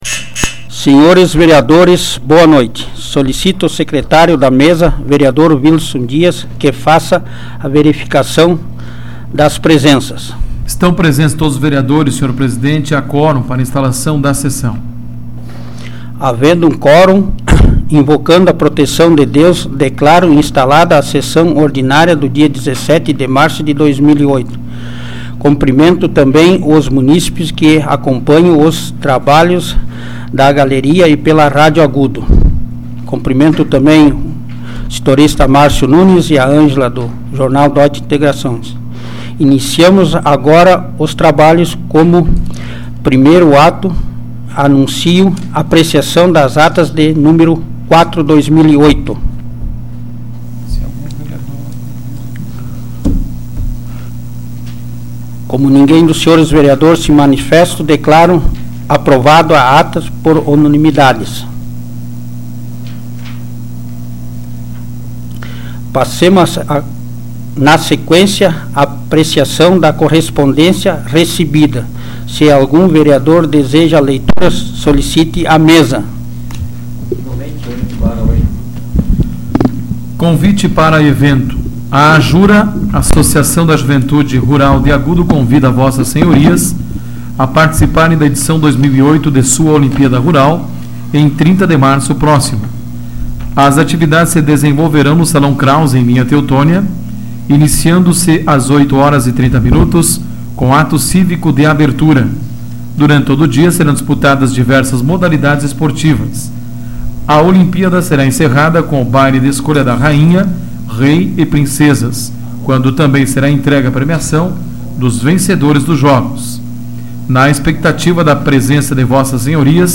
Áudio da 114ª Sessão Plenária Ordinária da 12ª Legislatura, de 17 de março de 2008